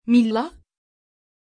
Pronunția numelui Milla
Turco
pronunciation-milla-tr.mp3